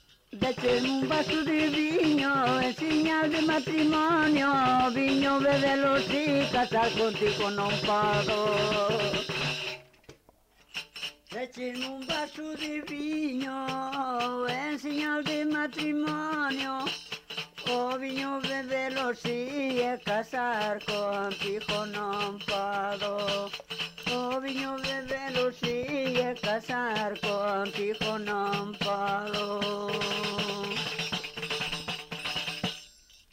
Coplas muiñeira -